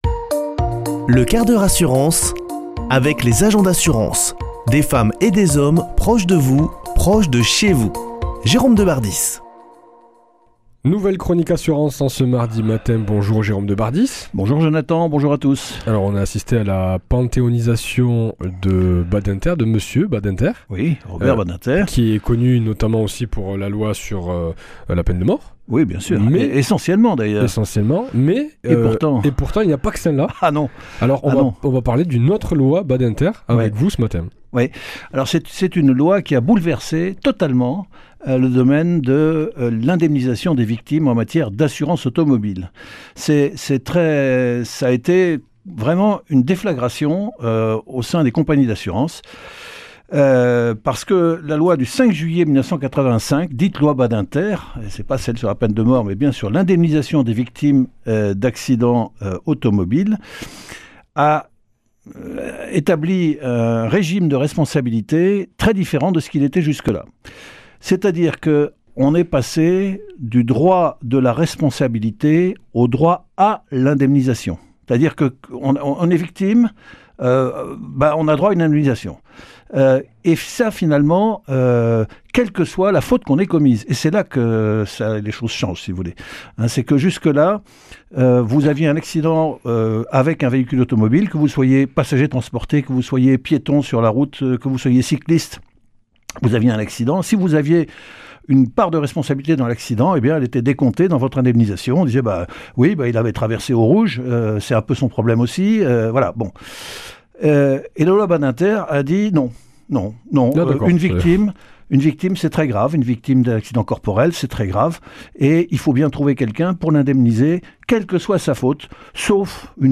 Une émission présentée par
Chroniqueur